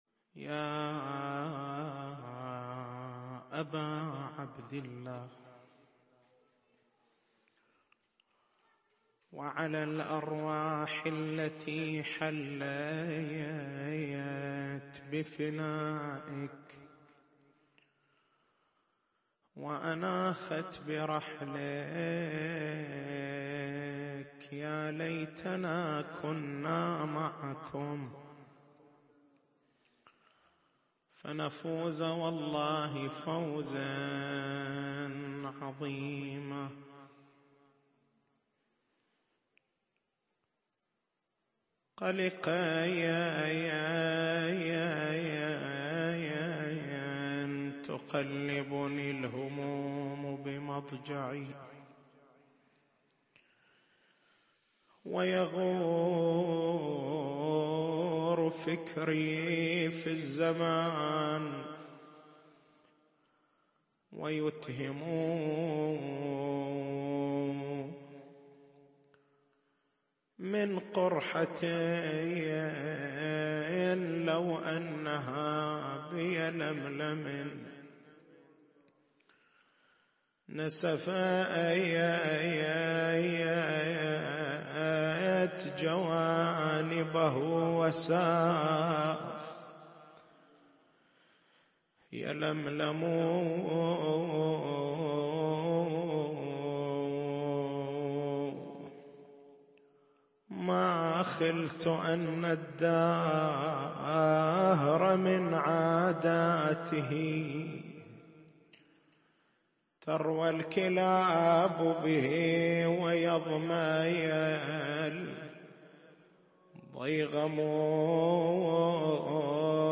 تاريخ المحاضرة: 17/01/1434 نقاط البحث: كيف نعمّم استحباب الجزع لغير مصيبة سيّد الشهداء (ع) من مصائب المعصومين (ع)؟ من المسؤول عن تحديد مصاديق الجزع؟